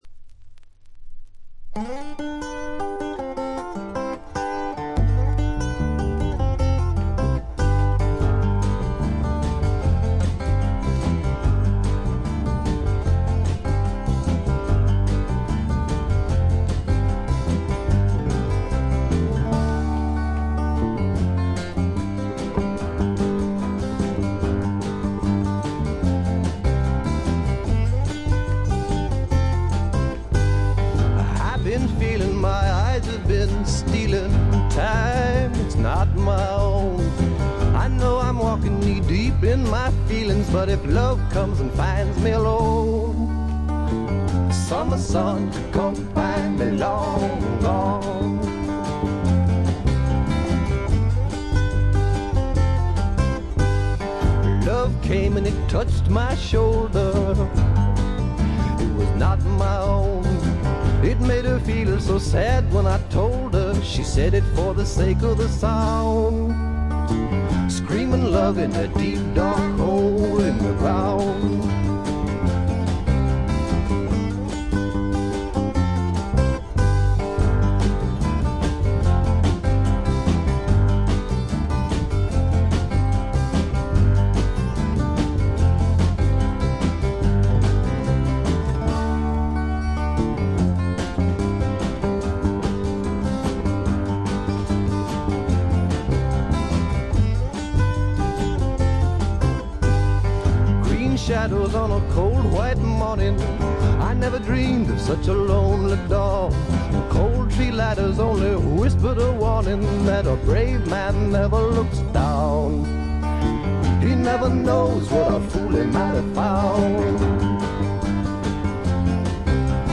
ごくわずかなノイズ感のみ。
試聴曲は現品からの取り込み音源です。
guitar, background vocals
bass, fiddle
drums, percussion